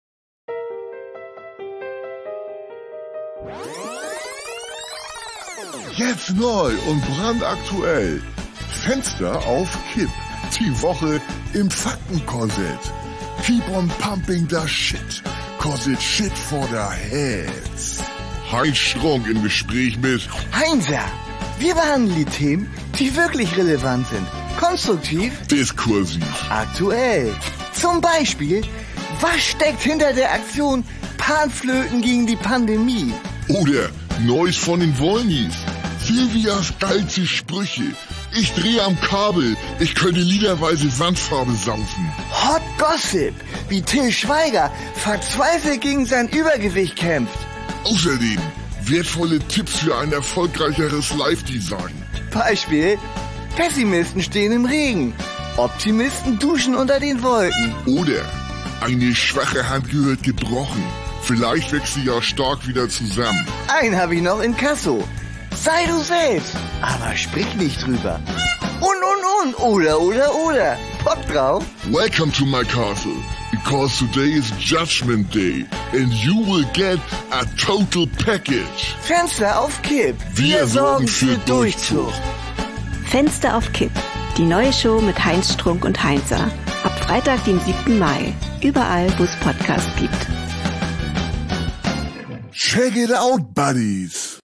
Trailer
eine Audio-Sitcom von Studio Bummens